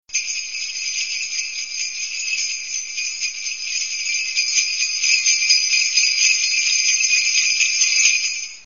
Sleigh Bells